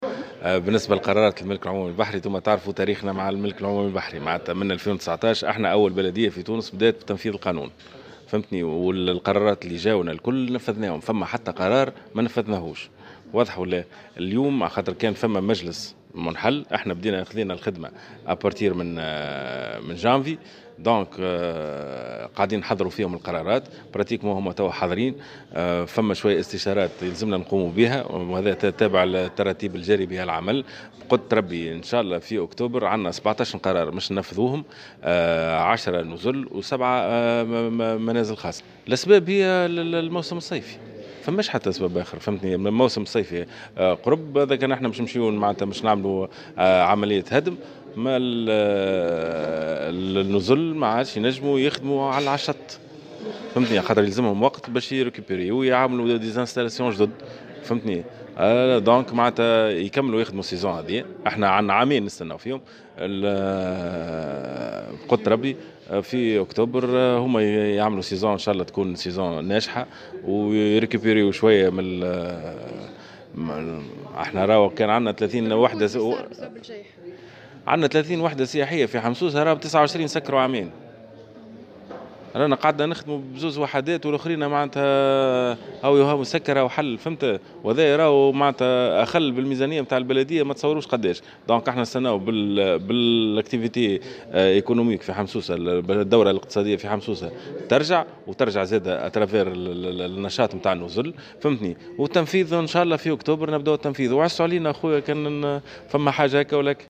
وأكد أنيس جغام، في تصريح للجوهرة اف ام، اليوم الأربعاء، على هامش الاحتفالات بالذكرى 43 لانبعاث مرسى القنطاوي، أن المجلس البلدي الجديد، الذي تسلم المسؤولية مطلع العام الجاري، بصدد وضع اللمسات الأخيرة على 17 قرار إزالة وهدم جديد، سيتم الشروع في تنفيذها خلال شهر اكتوبر القادم، أي بعد انتهاء الموسم الصيفي.